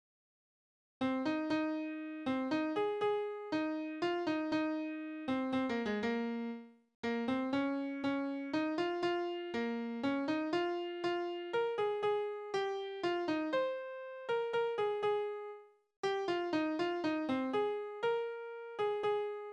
Tonart: As-Dur
Taktart: 3/4
Tonumfang: große Dezime
Besetzung: vokal